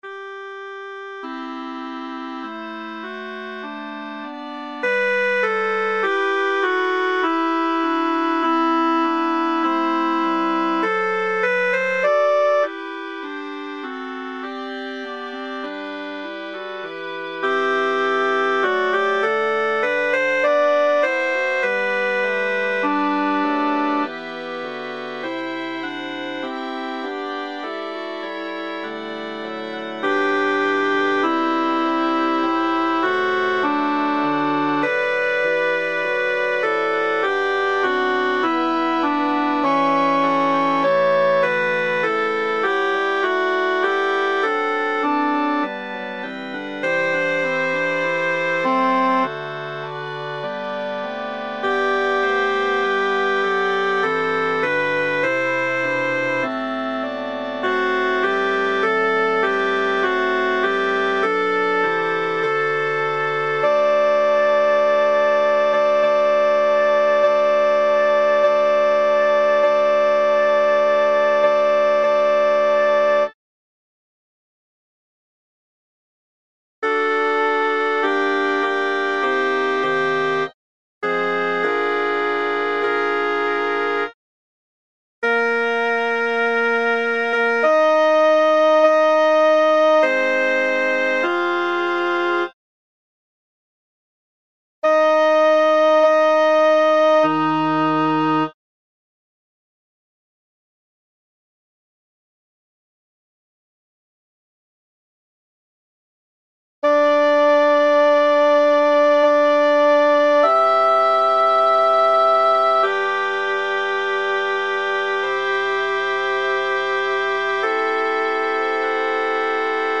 Credo_b   Sanctus à 8 voix mp3
Les fichiers mp3 (de grandes dimensions) sont faits à partir des fichiers midi (de très petites dimensions), avec la voix sélectionnée accentuée.